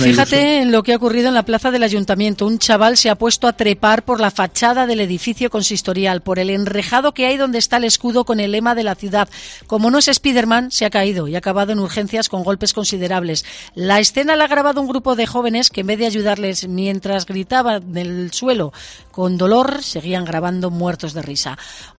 Así hemos contado en COPE la caída de un joven que intentó trepar por el Ayuntamiento de Oviedo